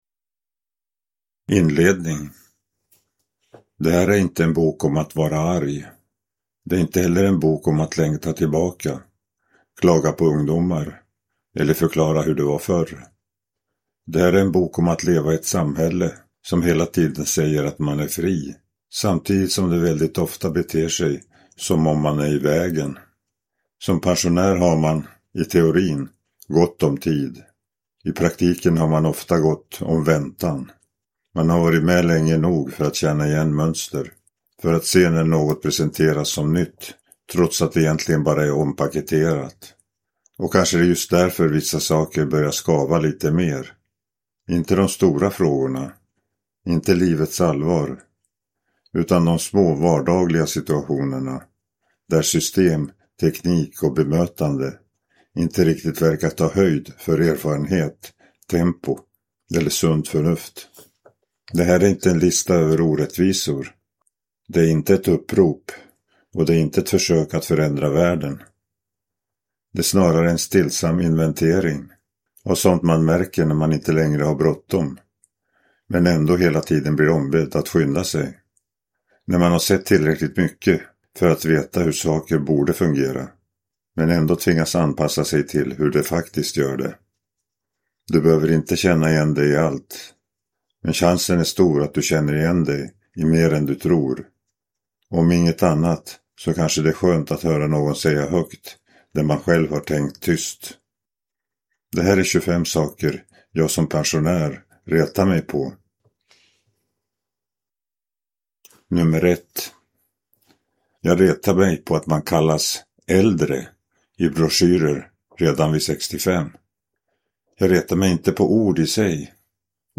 25 saker jag som pensionär retar mig på: vardagliga irritationer i ett liv med erfarenhet (ljudbok) av Rolf Jansson